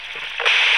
walkietalkie_eot